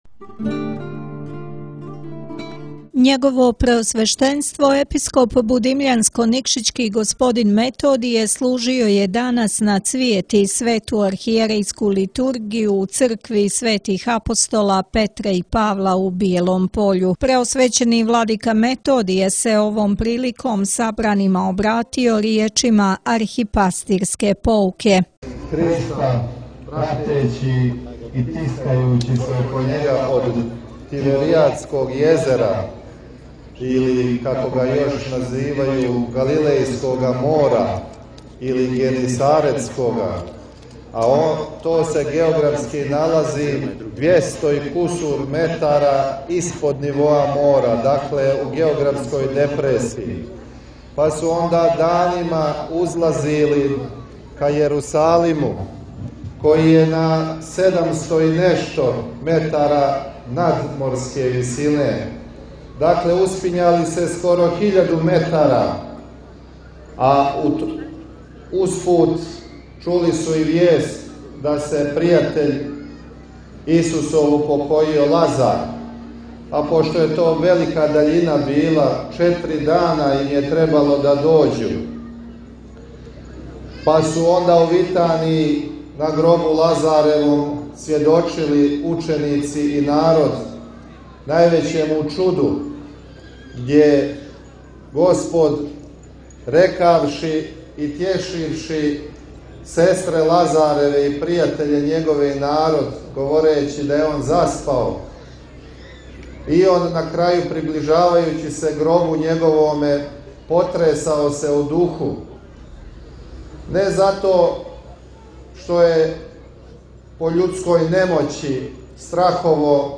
Архијерејска Литургија и литија на Цвијети у Бијелом Пољу: Идемо тихо, у тишини, за Христом на Голготу • Радио ~ Светигора ~
Његово Преосвештенство Епископ будимљанско-никшићки Г. Методије служио је на празник Уласка Господа Исуса Христа у Јерусалим – Цвијети, у недељу 28. априла 2024. године, Свету Архијерејску Литургију у цркви Светих апостола Петра и Павла у Бијелом Пољу.